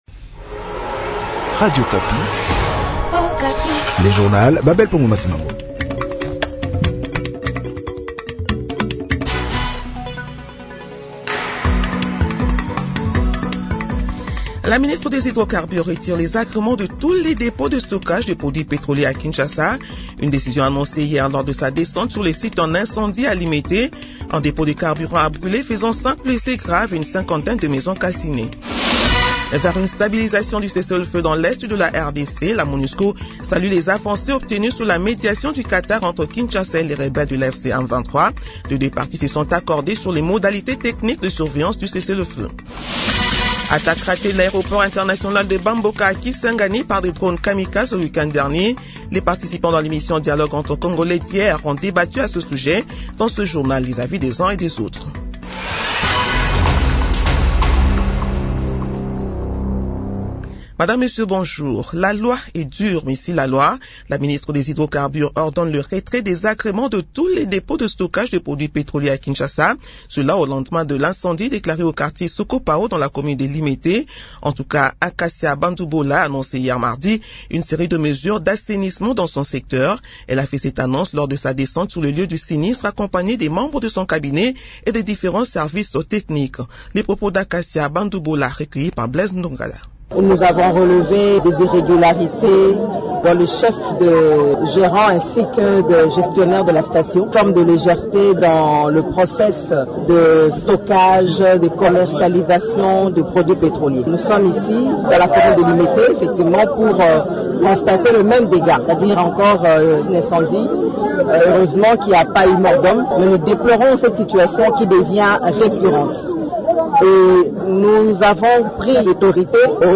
Journal Matin/7 heures